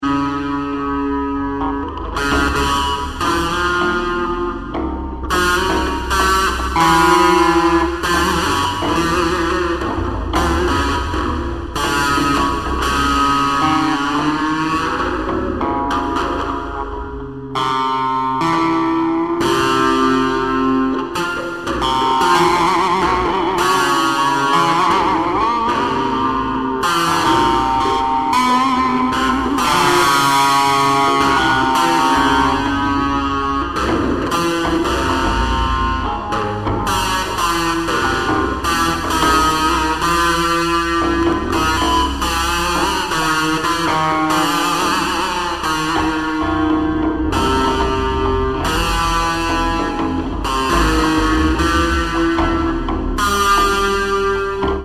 Solo guitar improvisations, variously layered
Gibson ES-335 guitar